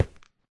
Sound / Minecraft / step / stone5